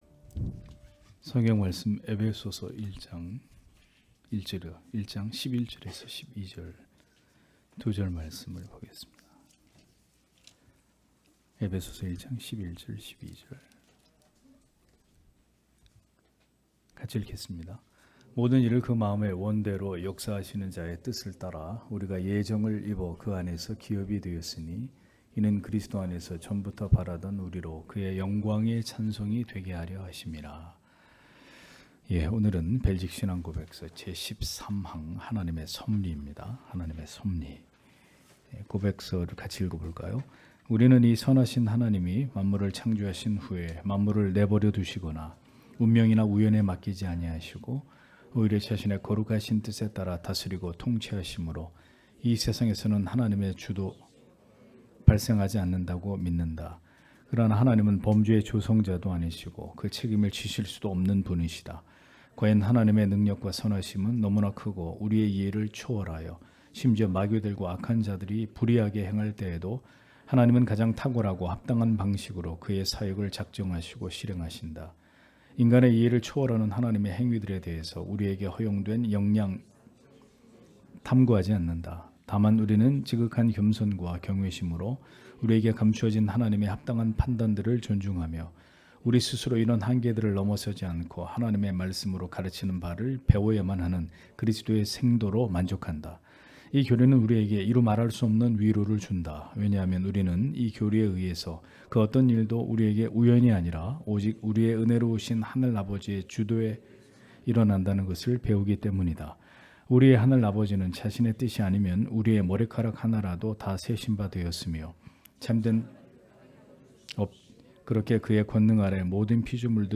주일오후예배 - [벨직 신앙고백서 해설 13] 제13항 하나님의 섭리 (에베소서 1장 11-12절)